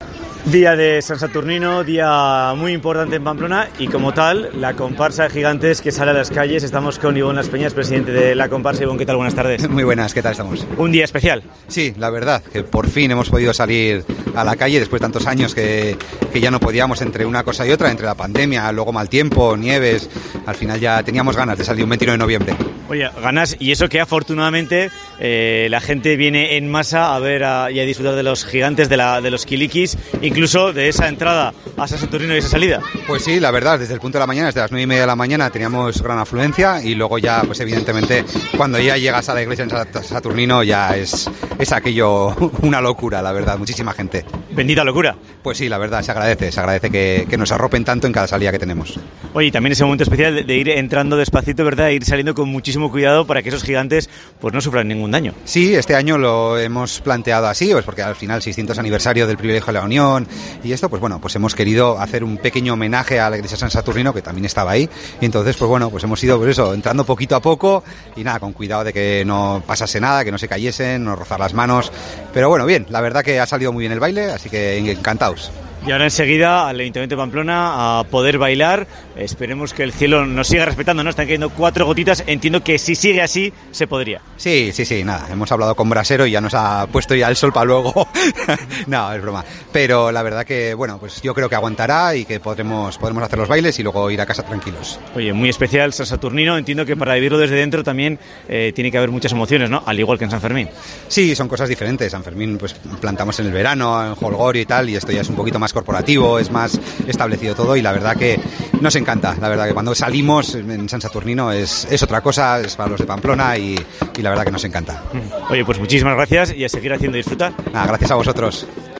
habla en COPE en San Saturnino